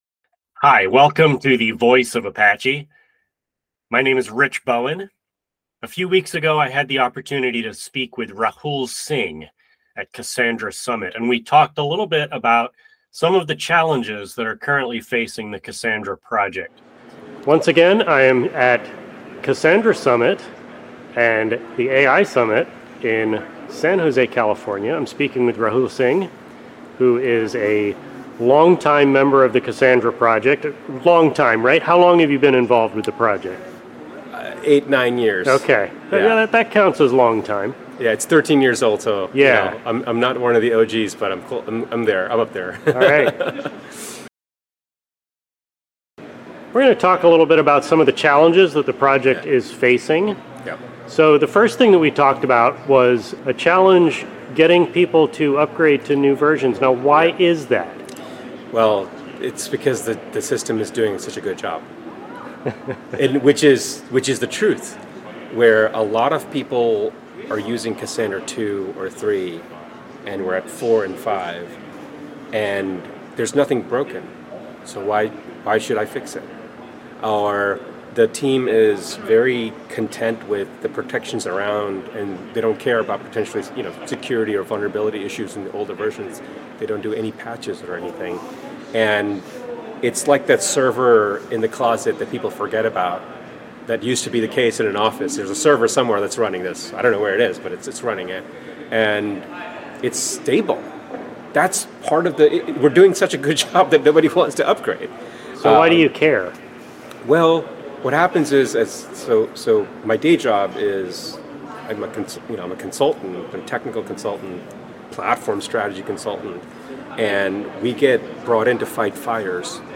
At Cassandra Summit 2023, in San Jose California